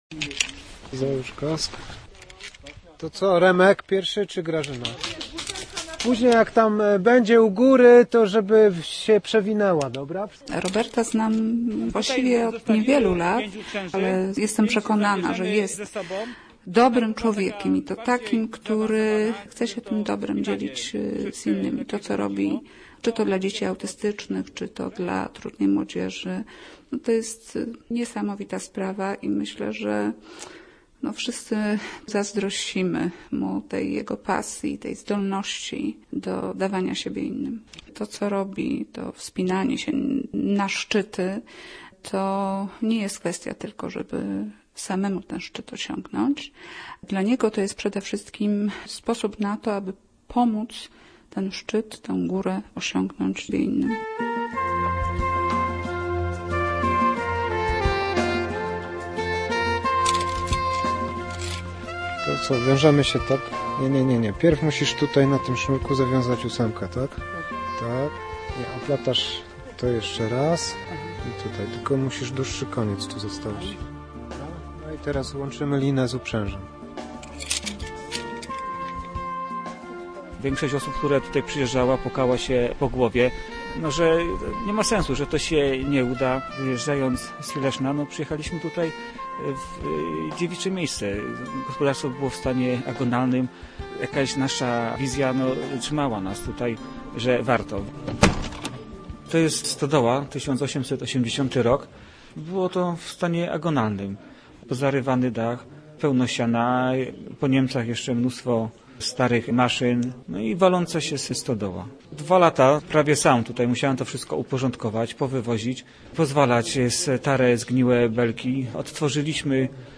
Zapraszamy na reportaż o człowieku, który wyjechał z Leszna i przeniósł się w góry.Teraz pomaga innym wspinać się na szczyt... swoich możliwości. Przekonuje, że każdy ma swój Mount Everest.